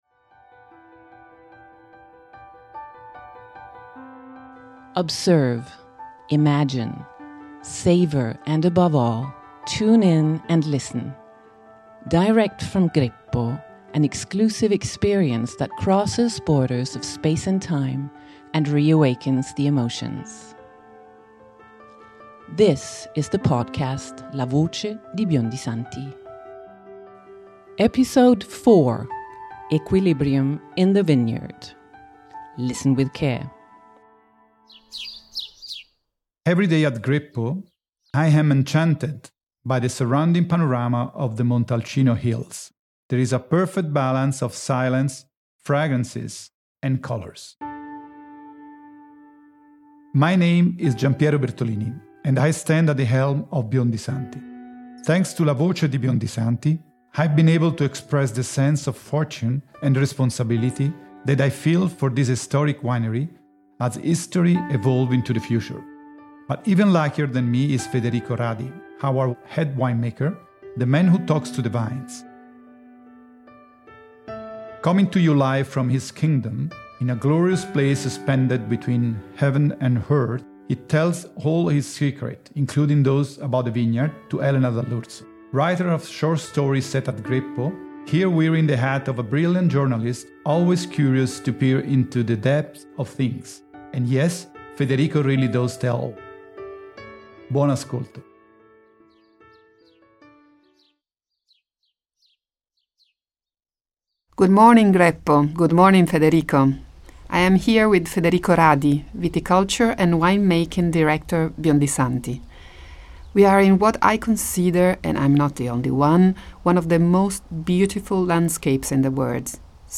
In an interview conducted in the Greppo vineyards